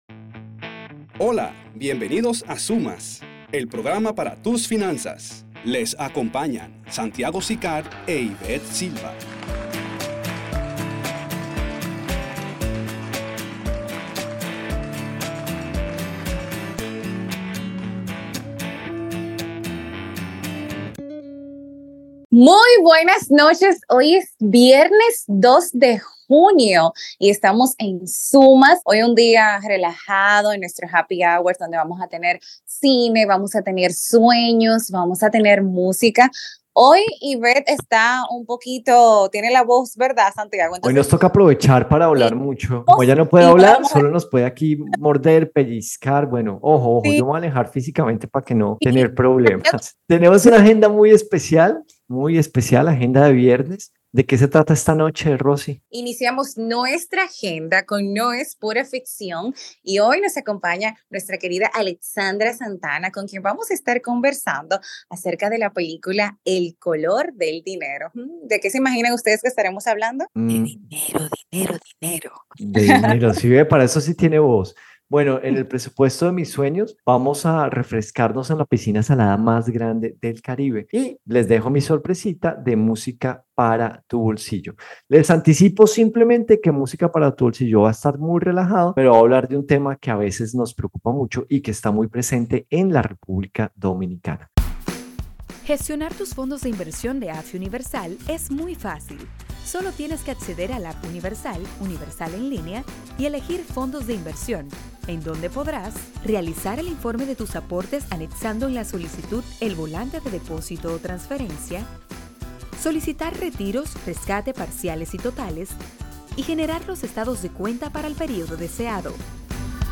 Escucha todo nuestro prrograma de radio de hoy.